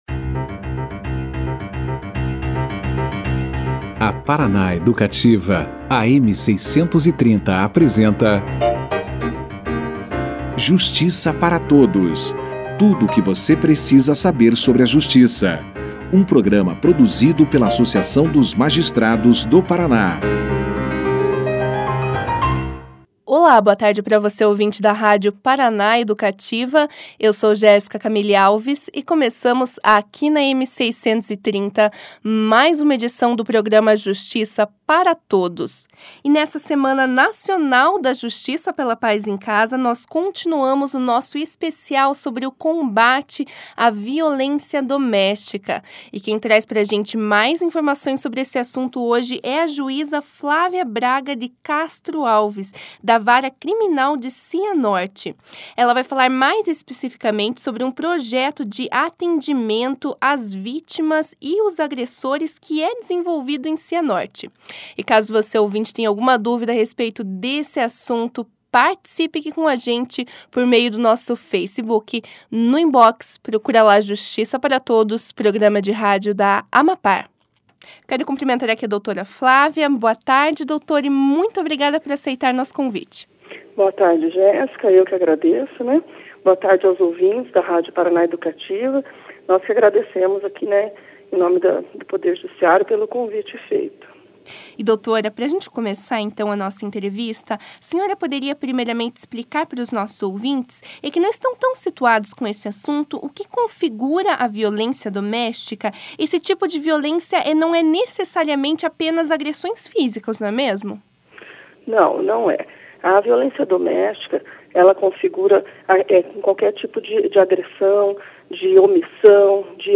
Na quinta-feira (23) em continuação a Semana Nacional Justiça pela Paz em Casa, o Justiça para Todos conversou com a juíza Flávia Braga de Castro sobre o projeto, desenvolvido em Cianorte, de atendimento as vítimas de violência doméstica e aos agressores. A magistrada falou sobre o funcionamento do projeto e o trabalho realizado com os homens, visando uma maior conscientização a respeito da violência doméstica.